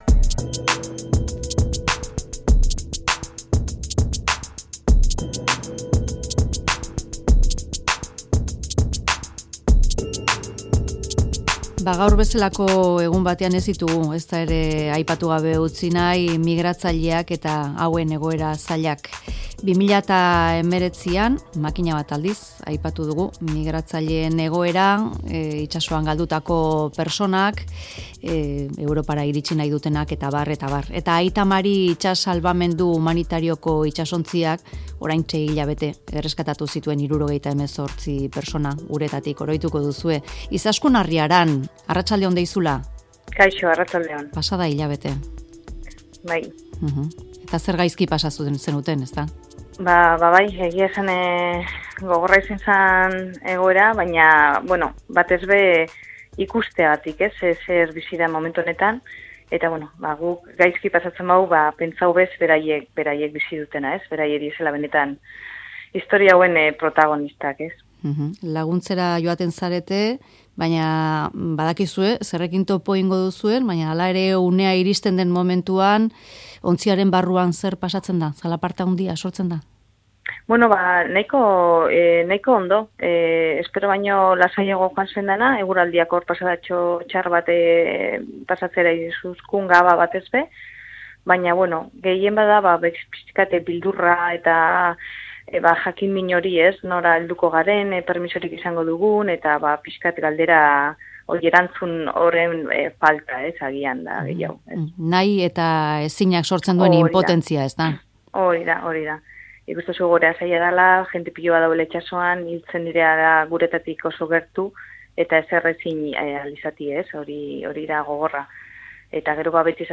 elkarrizketatu dugu